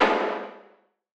Boomin - Hit #02.wav